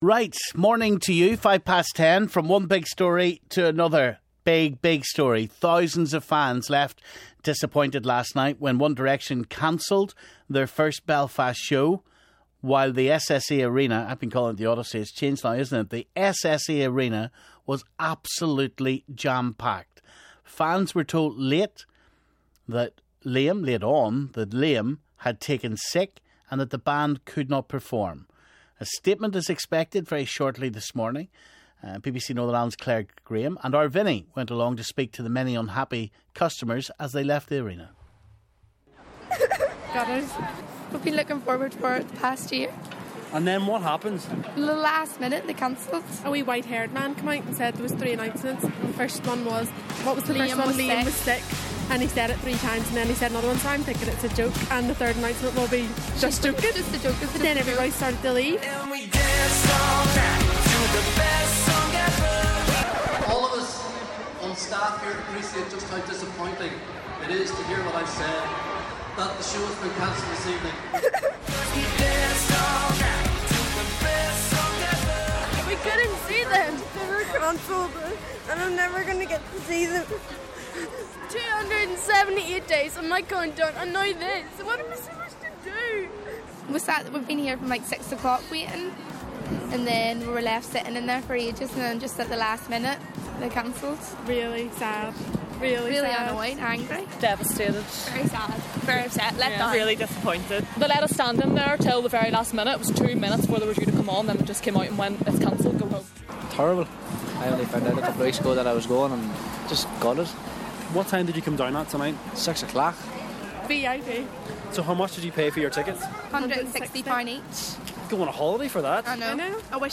Parents voice their disgust over One Direction show fiasco